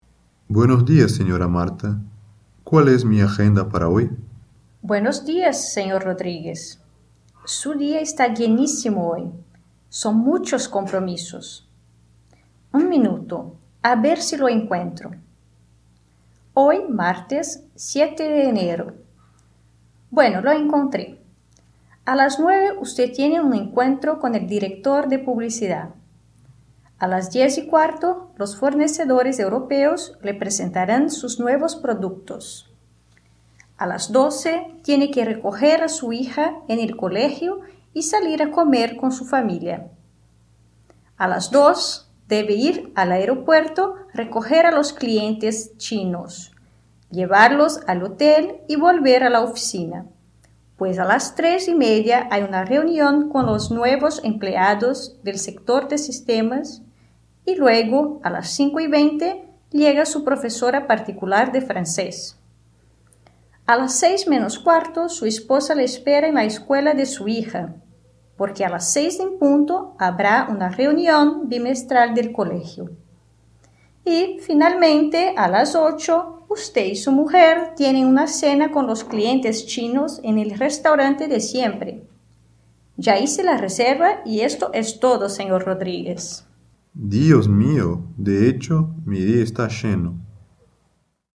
Description: Áudio do livro didático Língua Espanhola I, de 2008. Diálogo utilizando expressões coloquiais e do cotidiano.